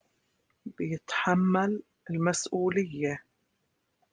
Jordanian